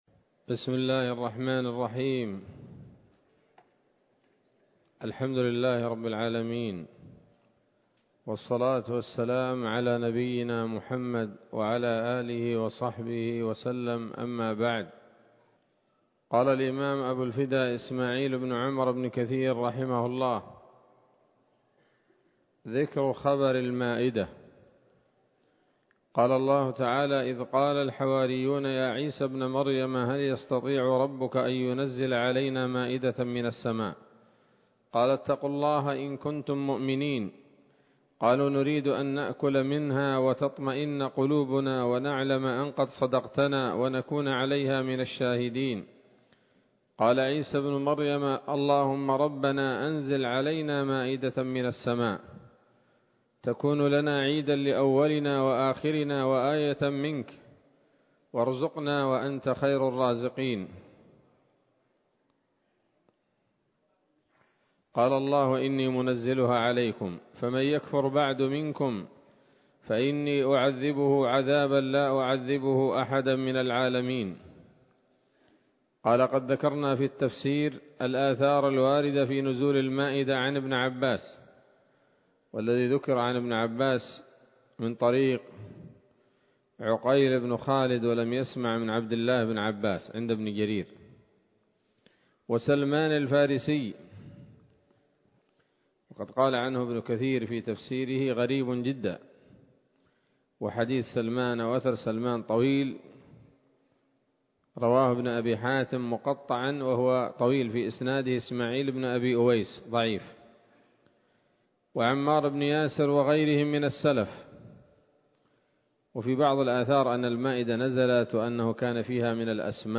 ‌‌الدرس التاسع والأربعون بعد المائة من قصص الأنبياء لابن كثير رحمه الله تعالى